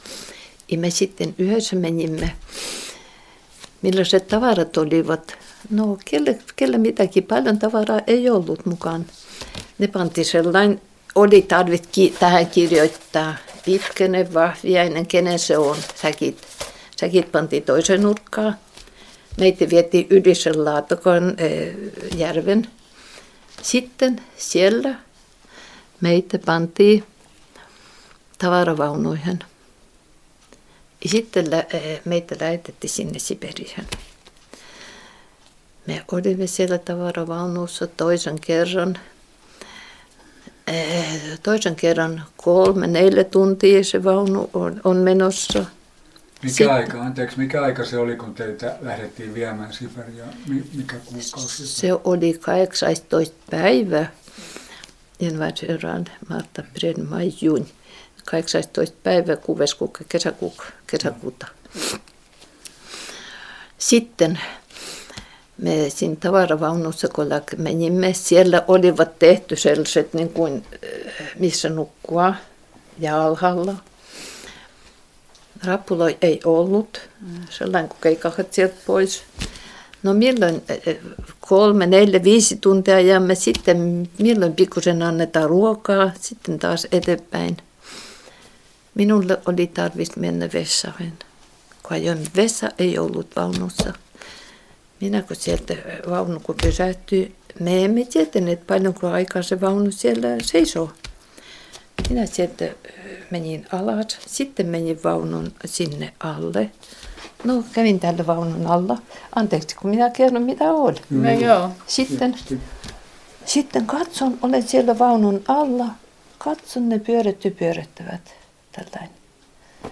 Inspelningarna innehåller huvudsakligen fritt tal av så kallade informanter, och berättelser om ämnen som de känner till, minnen och erfarenheter.
Inspelningarna gjordes från och med slutet av 1950-talet under intervjuresor med arkivets personal och insamlare som fått finansiering.